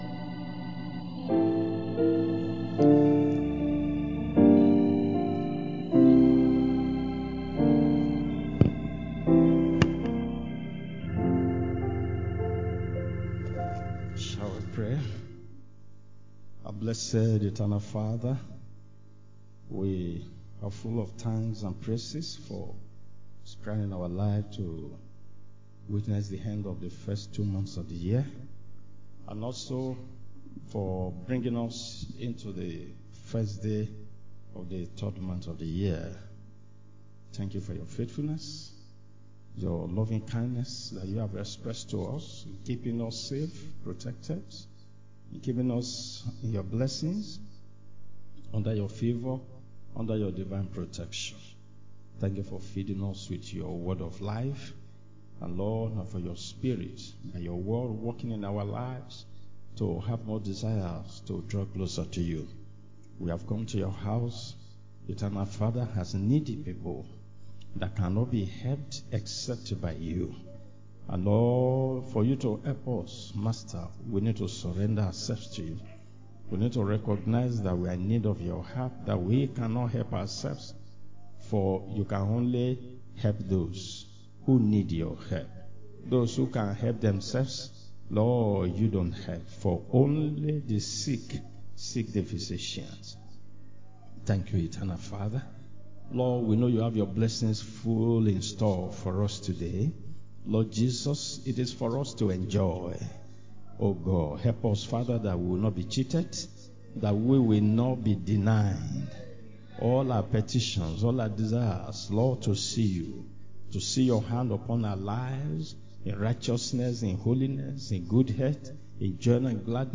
Sunday School Class